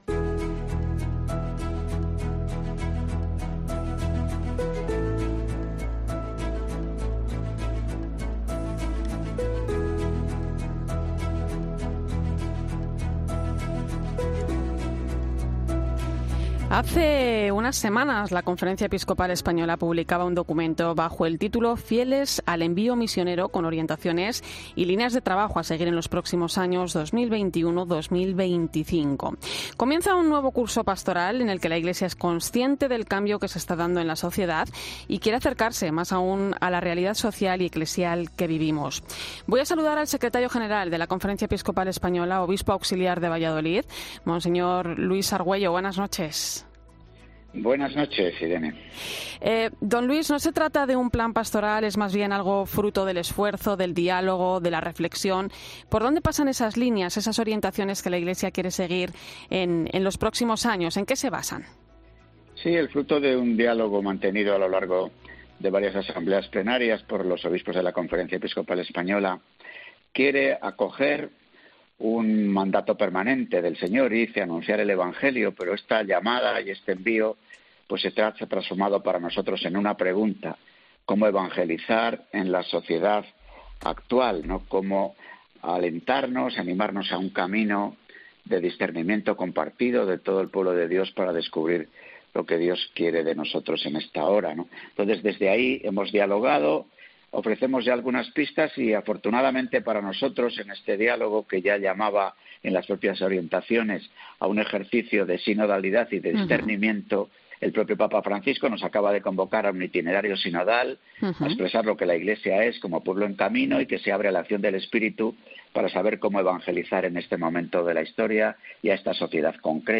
Para hablar de este documento ha participado en 'La Linterna de la Iglesia' monseñor Luis Argüello, secretario general de la Conferencia Episcopal y obispo auxiliar de Valladolid.